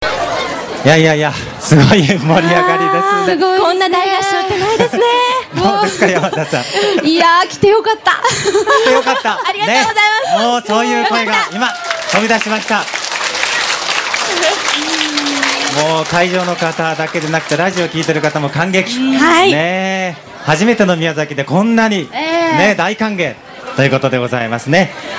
１９８８年５月のＧＷにサンリブマルショクはキャプテン翼のファンで賑わっていた。
山田栄子さんを中心にカラオケで唄ったが、その情熱たるやなにものにも吹き飛ばすほどだった。
「翼よ走れ！」を皆で唄いました。